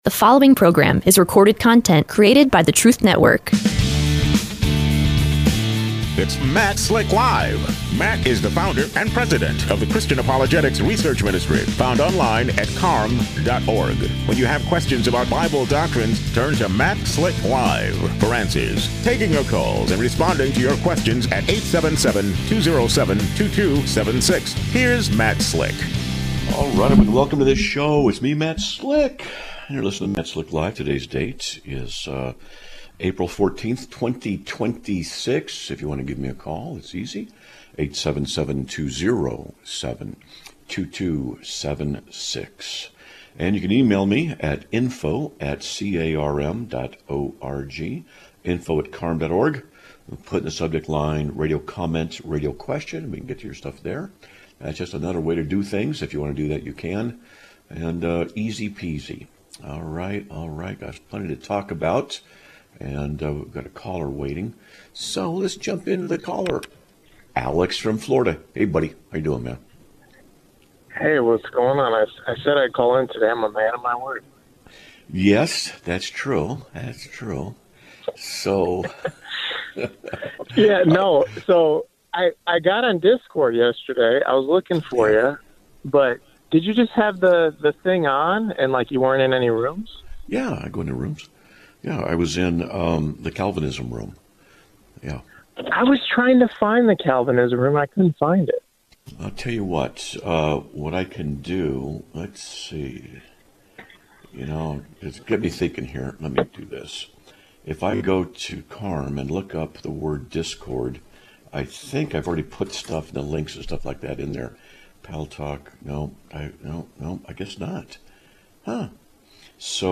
Live Broadcast of 04/14/2026
A Caller Wants Advice on How to Debate a Unitarian